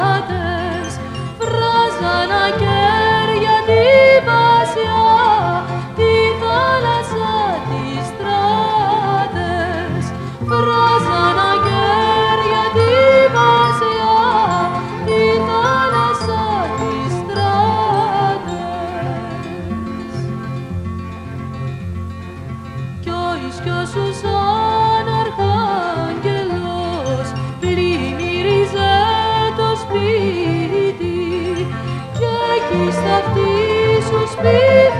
Vocal Pop Vocal Easy Listening French Pop
Жанр: Поп музыка